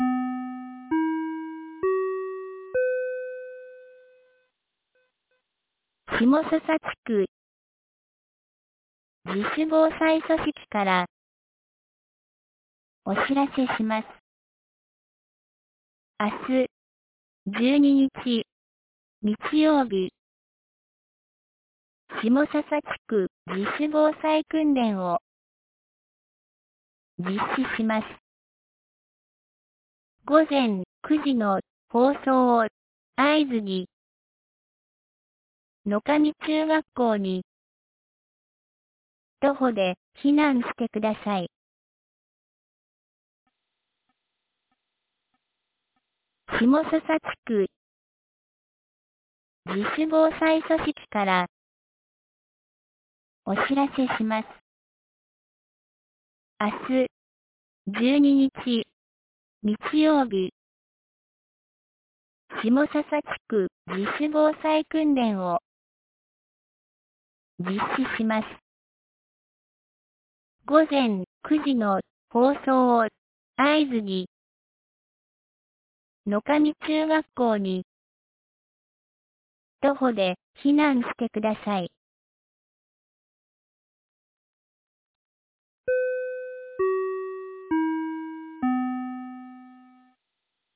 2023年11月11日 17時11分に、紀美野町より東野上地区へ放送がありました。